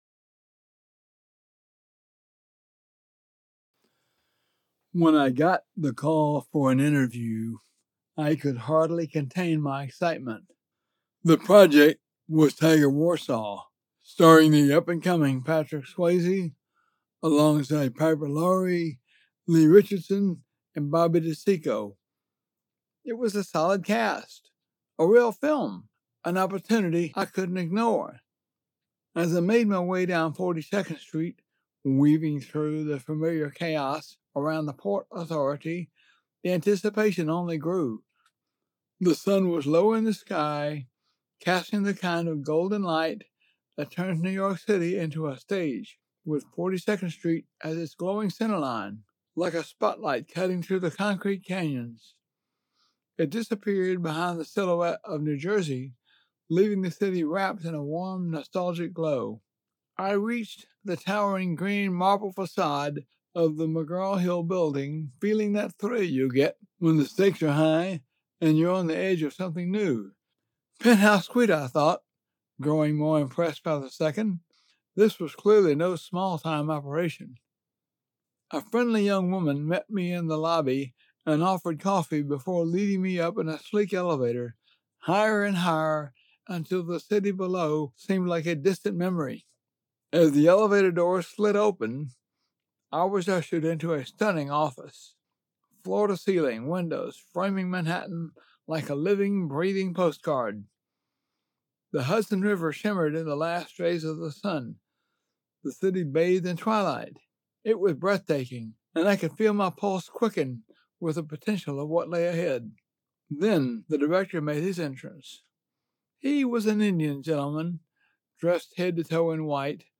American Southern senior citizen looking to voice some projects fit for him
Excerpt from a book by a Director of Photography
English - Southern U.S. English